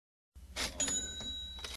ka-ching sound effect.mp3